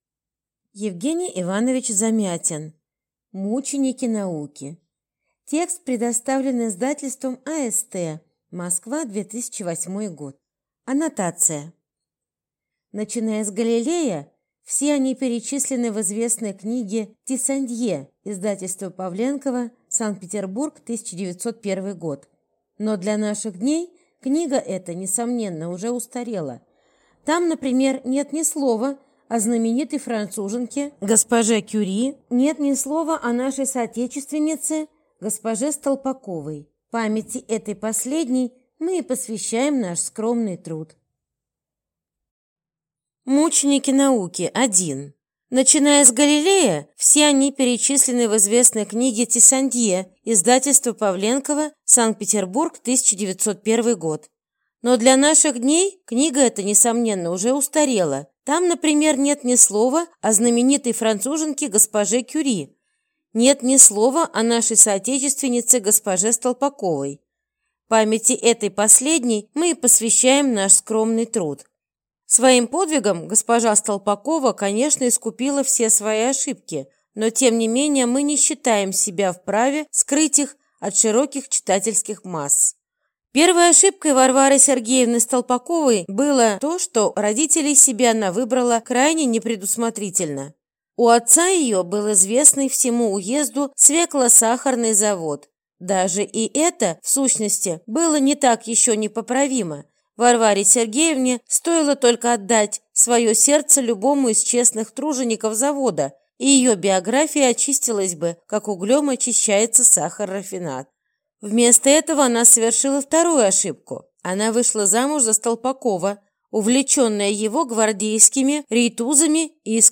Аудиокнига Мученики науки | Библиотека аудиокниг